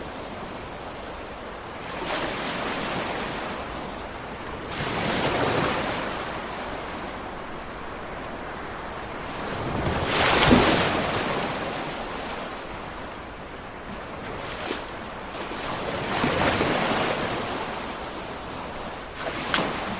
Free Nature sound effect: Calm Ocean.
Calm Ocean
# ocean # calm # peaceful # waves About this sound Calm Ocean is a free nature sound effect available for download in MP3 format.
465_calm_ocean.mp3